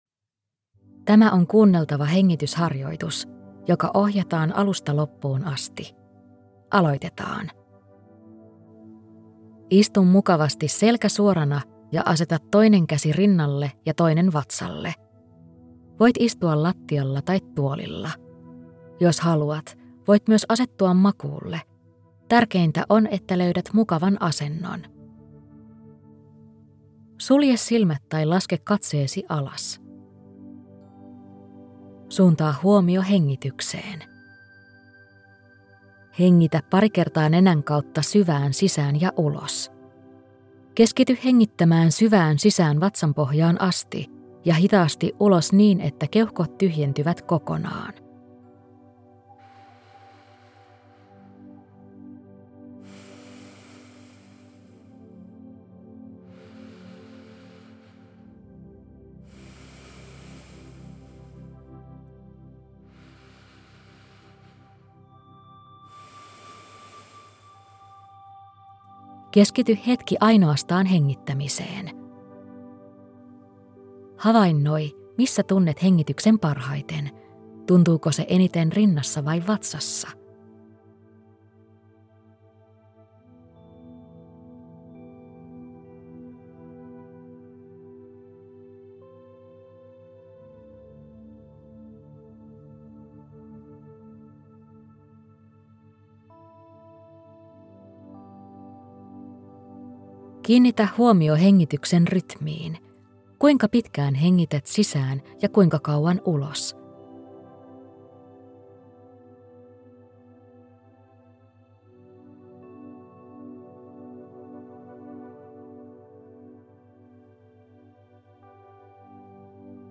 Tietoinen hengitys – kuunneltava hengitysharjoitus
• Äänite alkaa johdatuksella hengitysharjoitukseen.
• Sen jälkeen harjoitusta jatketaan 3 minuuttia omaan tahtiin.